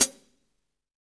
HAT ROOM 07R.wav